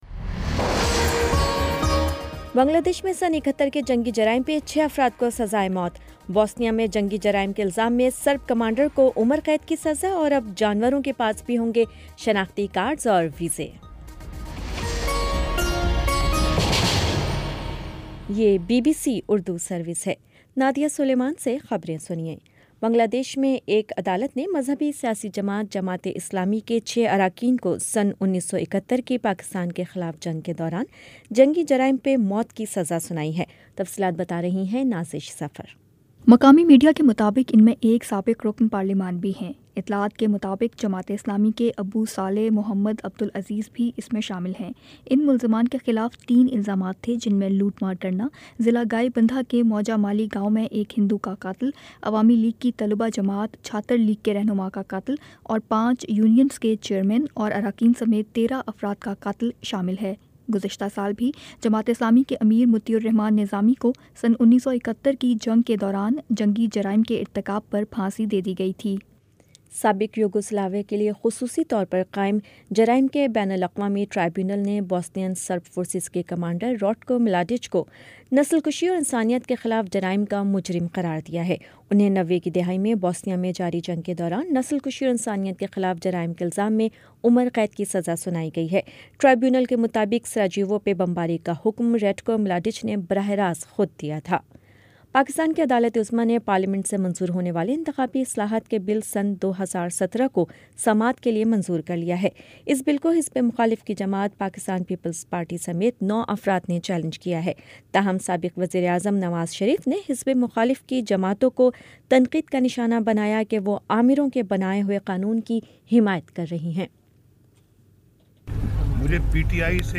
نومبر22 : شام چھ بجے کا نیوز بُلیٹن